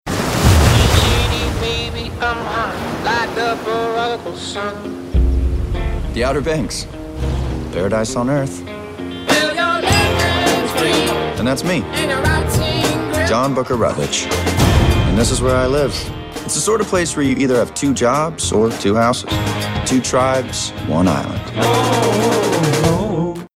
Музыкальная тема